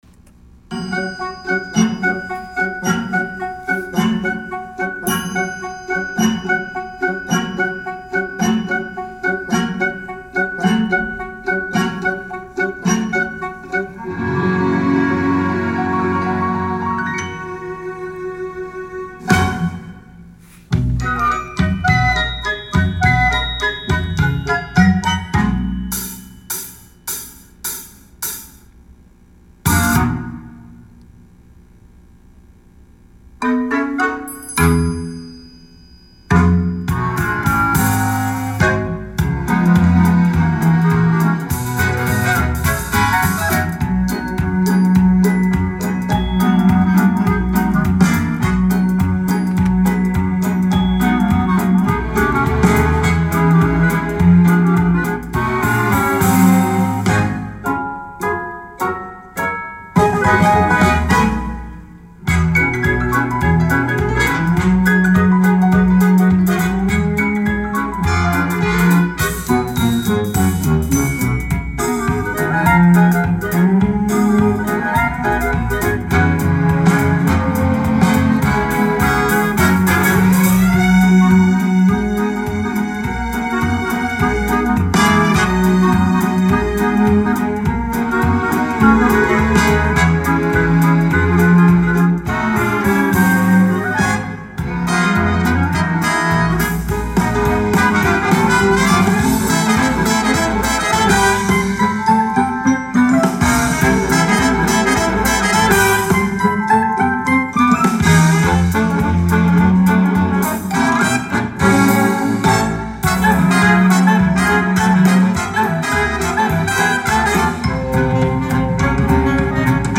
Sparklejollytwinklejingley Instrumental